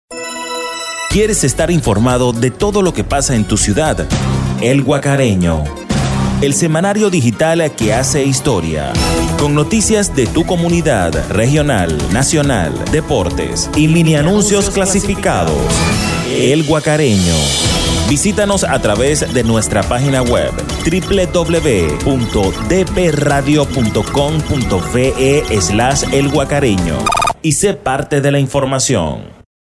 kolumbianisch
Sprechprobe: Industrie (Muttersprache):